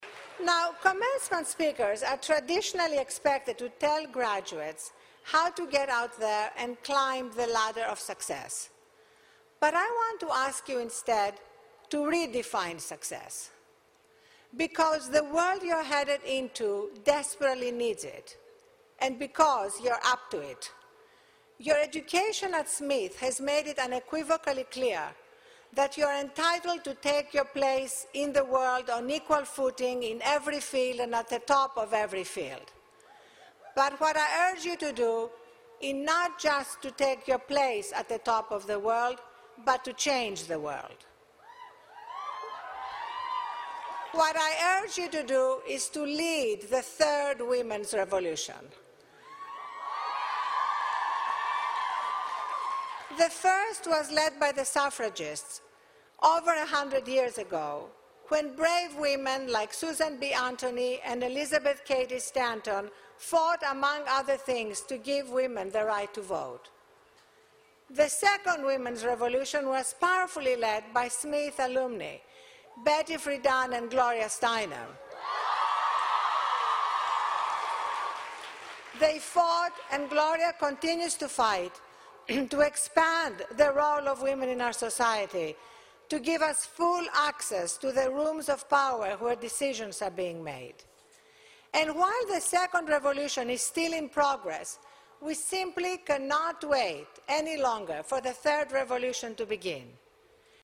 公众人物毕业演讲 第314期:阿丽安娜.哈芬顿2013史密斯学院(4) 听力文件下载—在线英语听力室